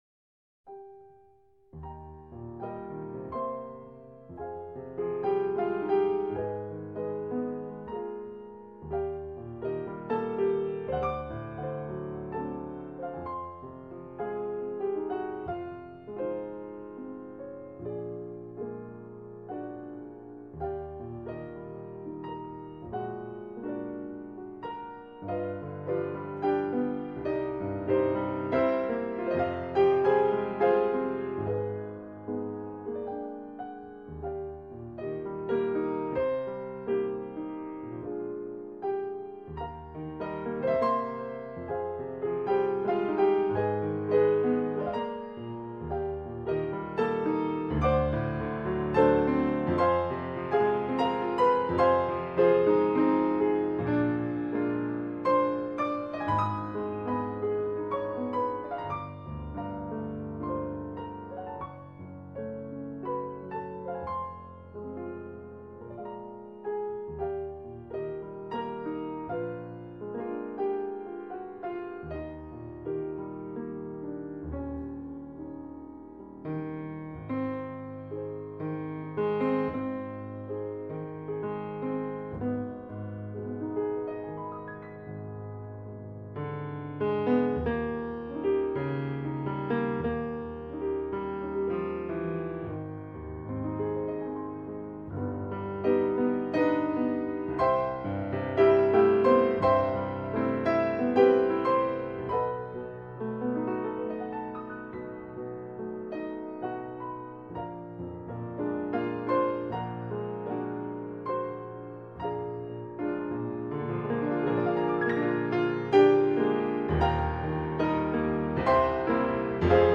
เพลงพระราชนิพนธ์